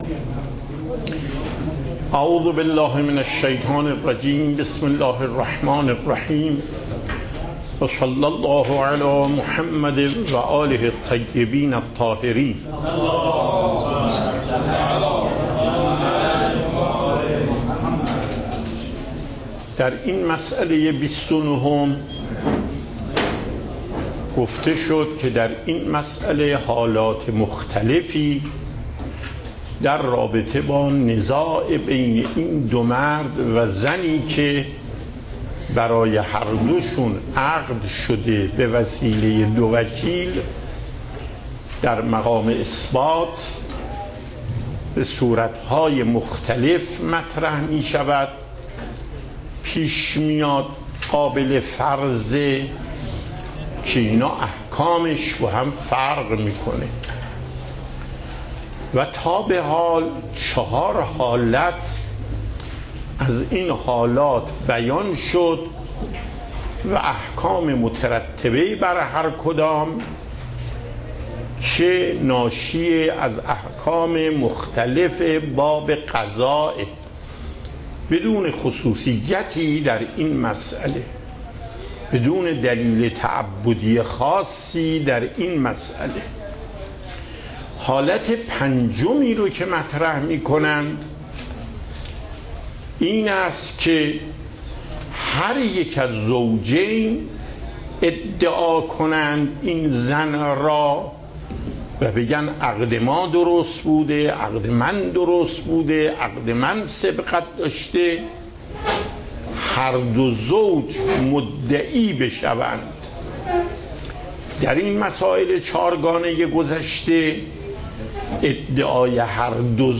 صوت و تقریر درس پخش صوت درس: متن تقریر درس: ↓↓↓ تقریری ثبت نشده است.
درس فقه آیت الله محقق داماد